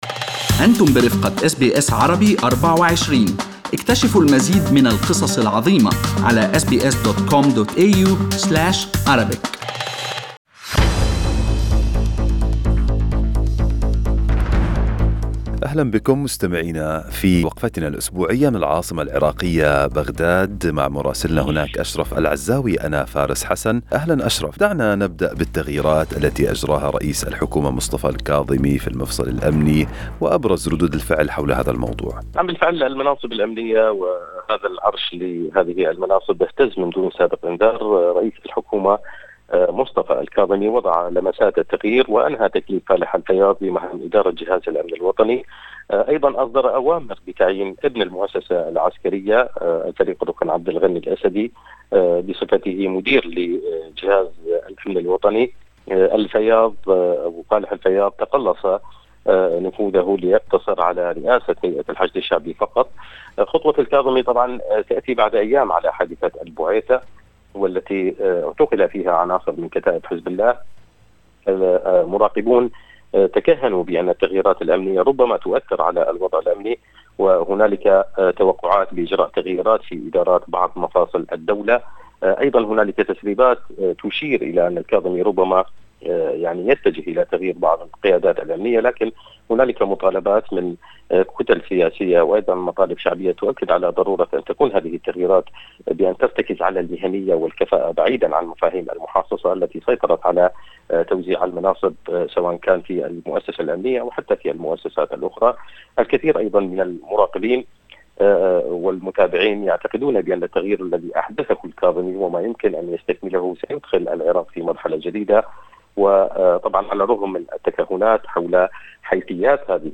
يمكنكم الاستماع إلى تقرير مراسلنا في العراق بالضغط على التسجيل الصوتي أعلاه.